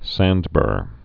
(săndbûr)